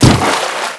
fs_water_colo1.wav